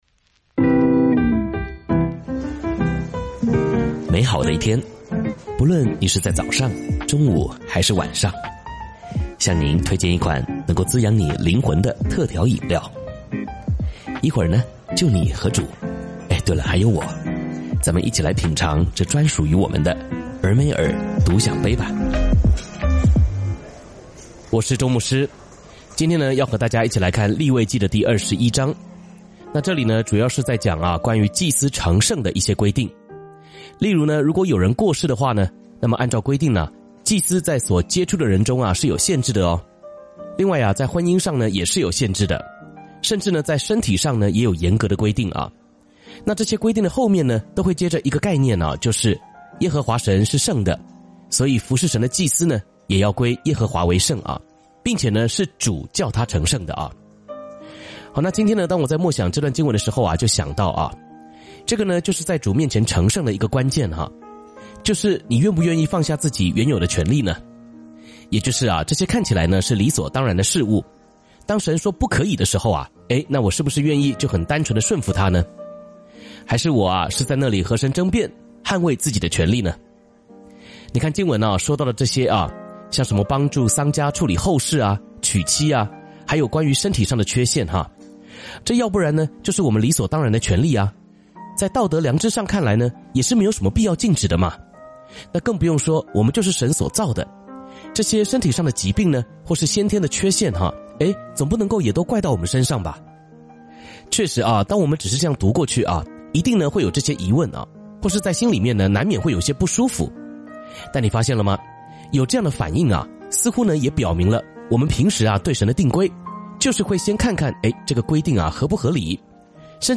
「天父爸爸說話網」是由北美前進教會Forward Church 所製作的多單元基督教靈修音頻節目。